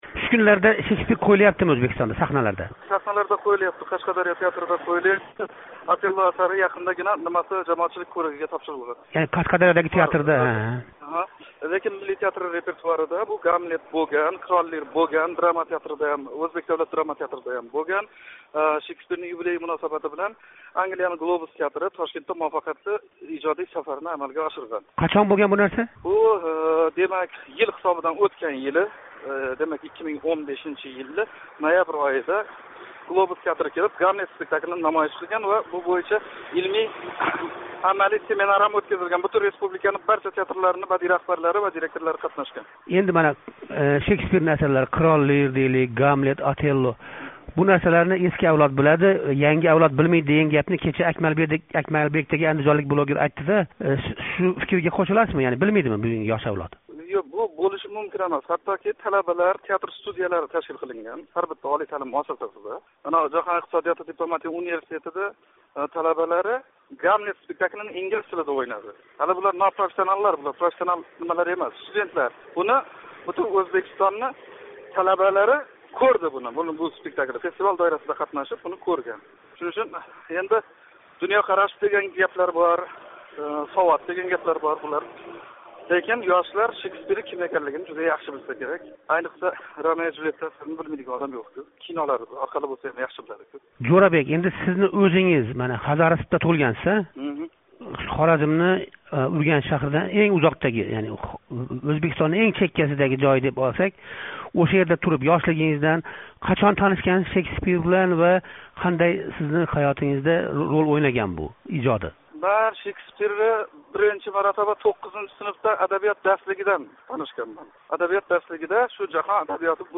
Chodirxayol: Romeo va Hamletni o‘ynagan o‘zbek aktyori bilan suhbat